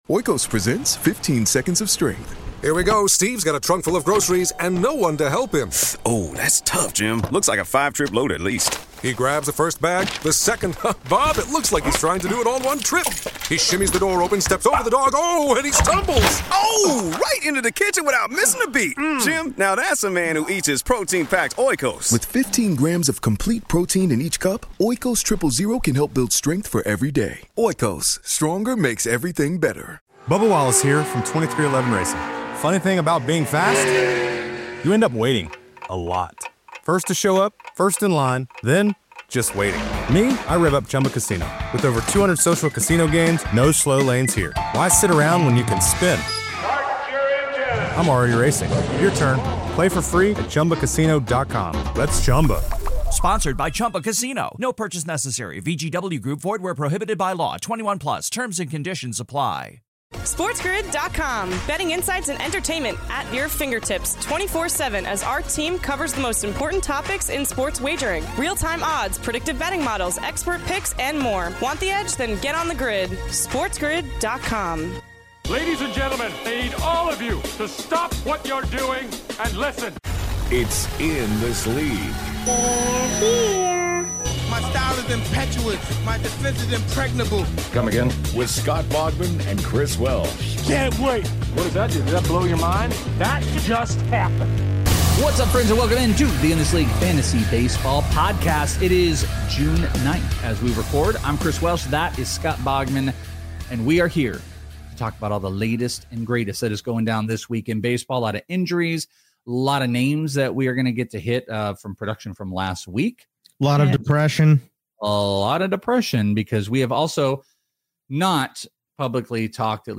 go live on twitch to break down week 12 of the MLB